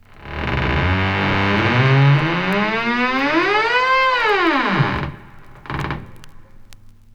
• heavy creaky door opens.wav
heavy_creaky_door_opens_ayI.wav